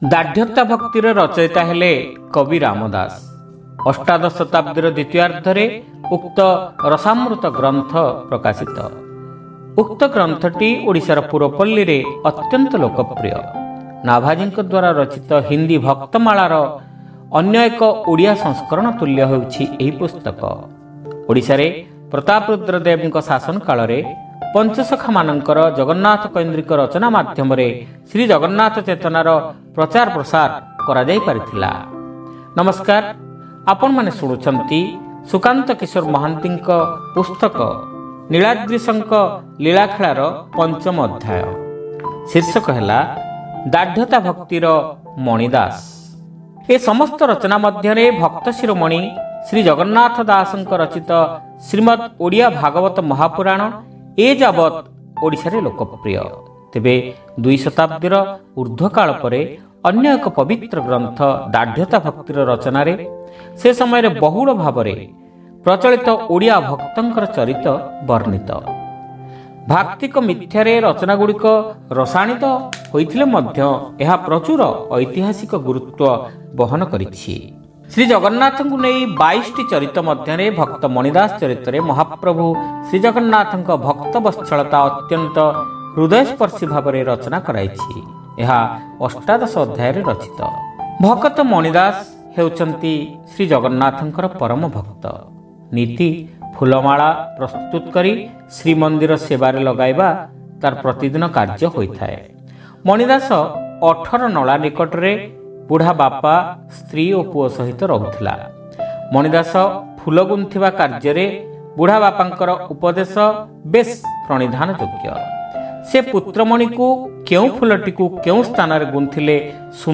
ଶ୍ରାବ୍ୟ ଗଳ୍ପ : ଦାଢ଼୍ୟତାଭକ୍ତିର ମଣି ଦାସ